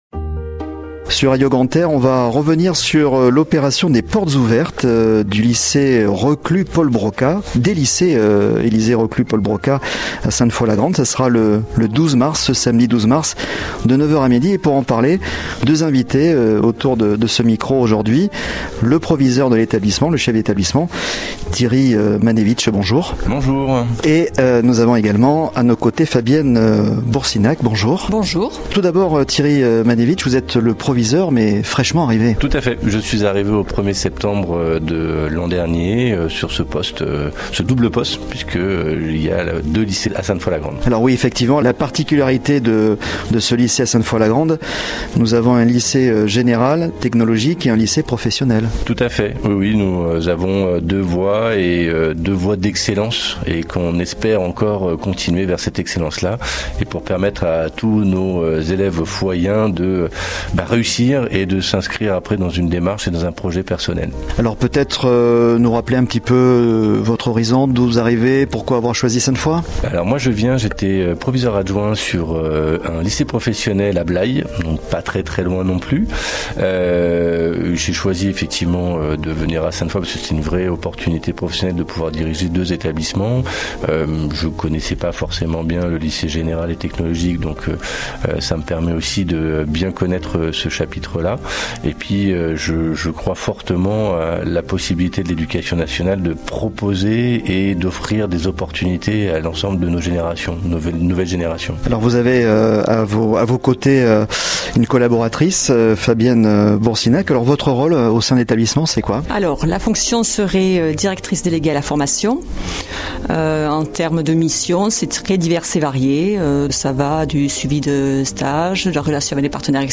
Le podcast des invités de Radio Grand "R" !